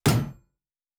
Shield Metal 7_5.wav